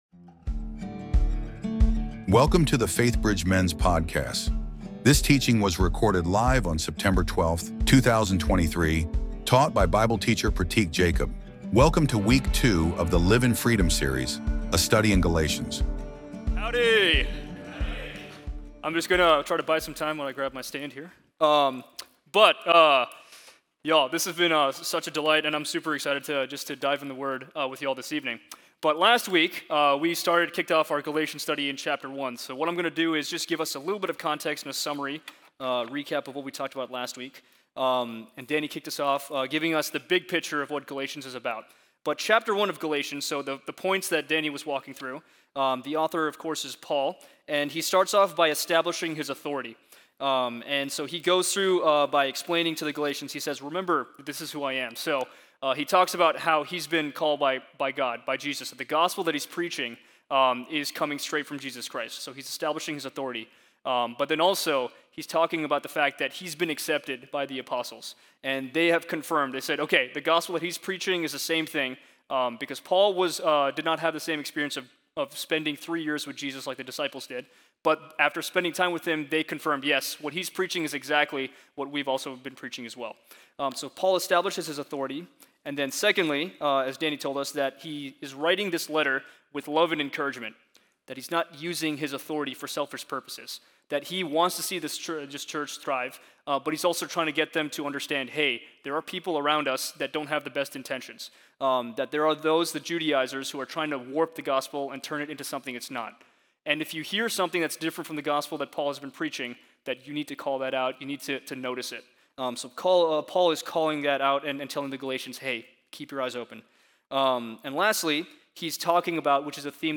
Week 2 of the Live in Freedom Series, a study in Galatians. Taught by Bible Teacher